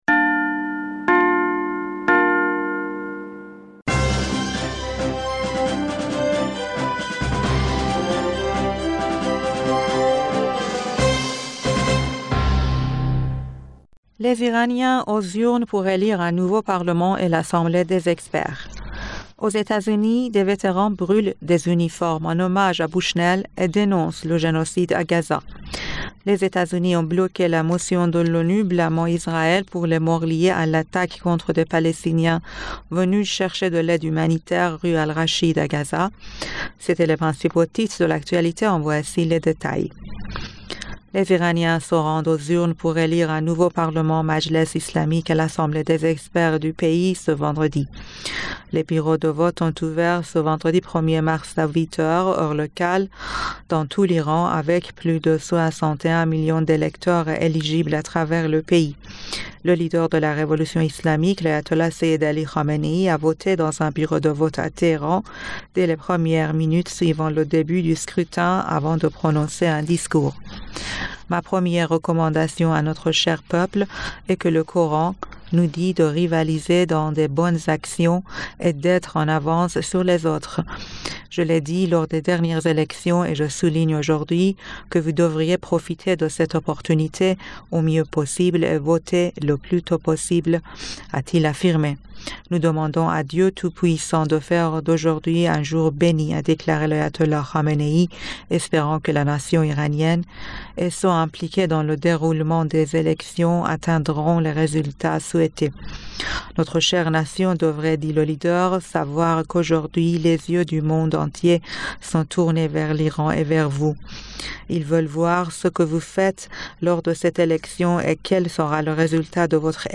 Bulletin d'information du 01 Mars 2024